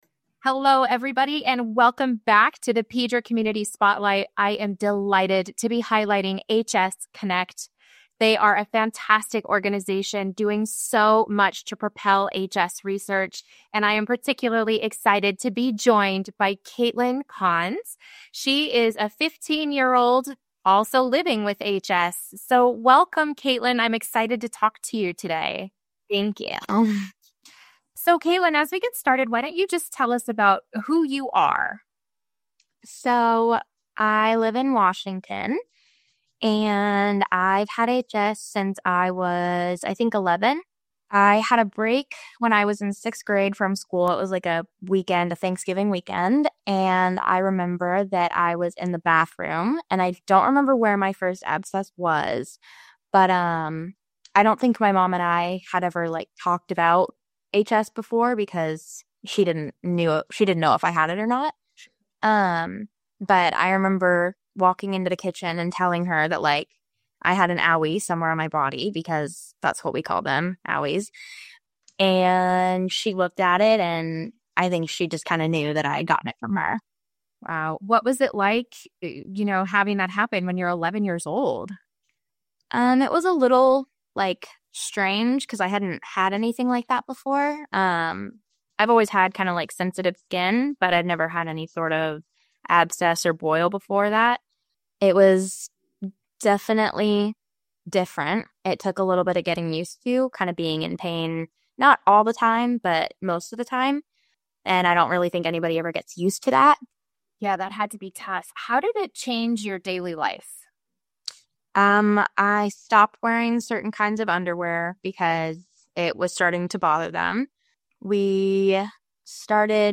2025-Family-Interview-converted.mp3